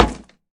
glass_place.ogg